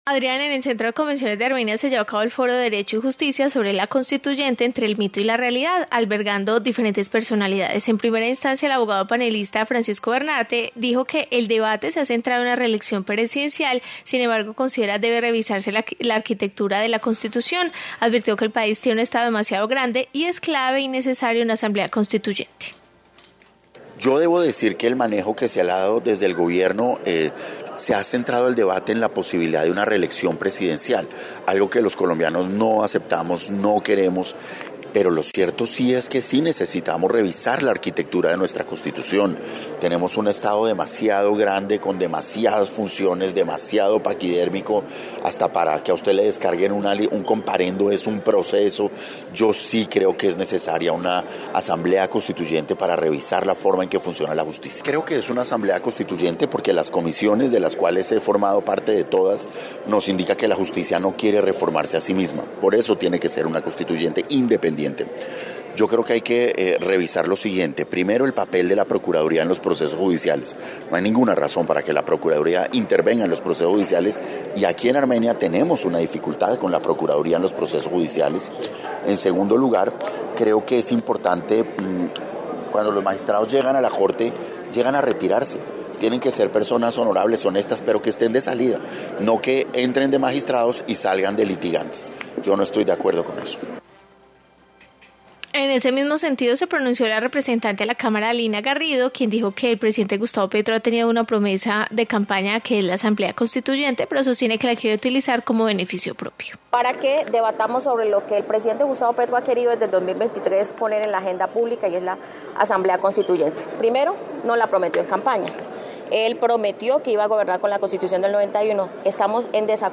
Informe sobre foro en Armenia